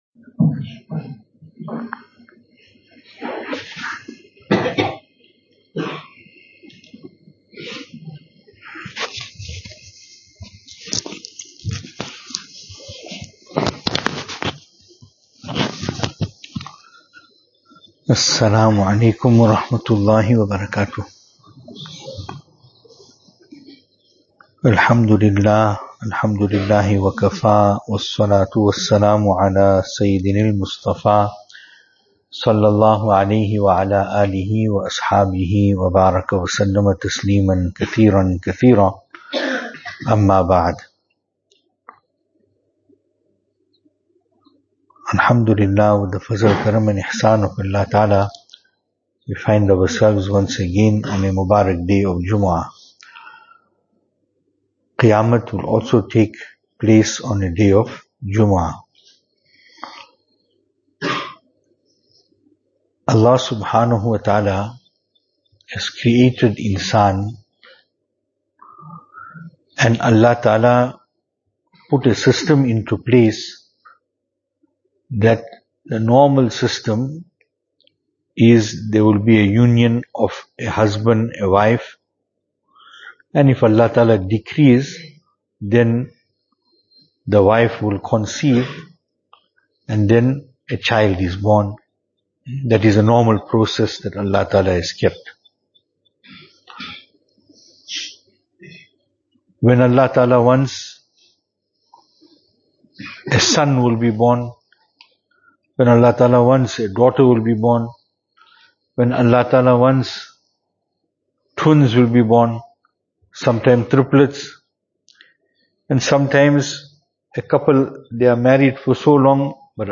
Why we cannot engage in interfaith gatherings Venue: Albert Falls , Madressa Isha'atul Haq Series: JUMUAH Service Type: Jumu'ah Topics: JUMUAH « Love for Allah Ta’ala is the solution to all situations we may face .